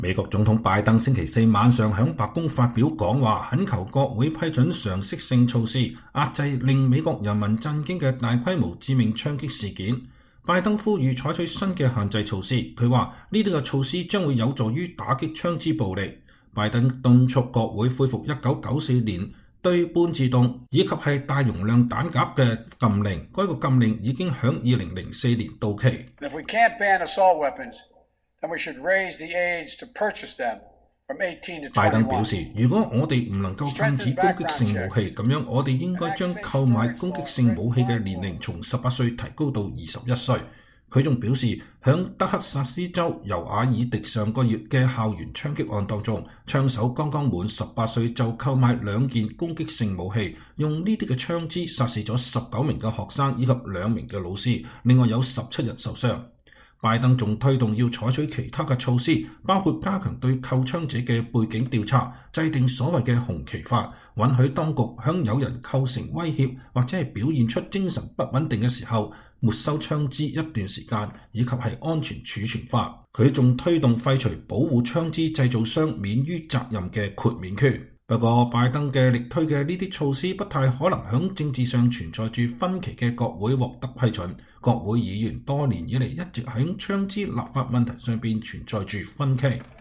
美國總統拜登星期四晚上在白宮發表講話，懇求國會批准“常識性措施”，遏制令美國人民震驚的大規模致命槍擊事件。拜登呼籲採取新的限制措施，他說，這些措施將有助於打擊槍支暴力。